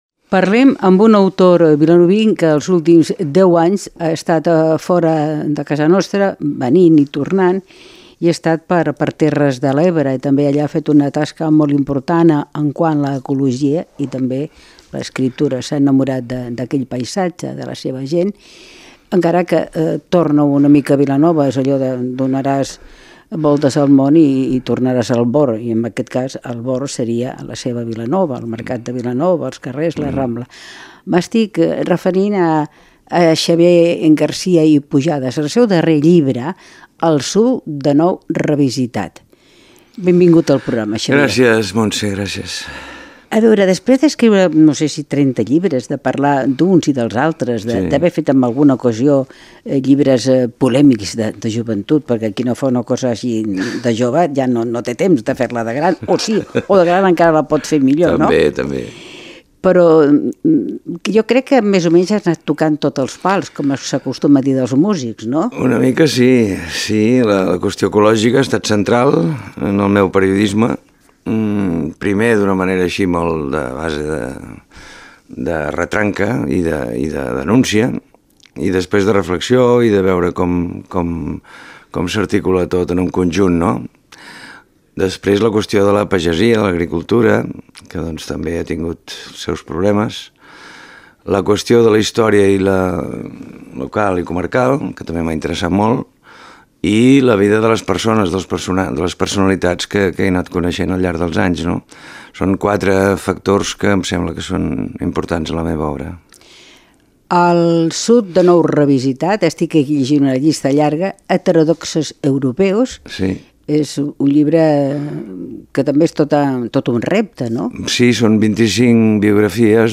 Paisatges humans: entrevista
Àudio: arxiu sonor i podcast de Ràdio Vilanova, Ràdio Cubelles i Ràdio Maricel